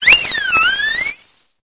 Cri de Nymphali dans Pokémon Épée et Bouclier.